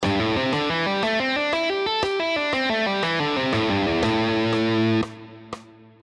Here are the five positions of the A minor pentatonic scale, all played in triplets:
Guitar Triplets Exercise 5 – A Minor Pentatonic Position 5: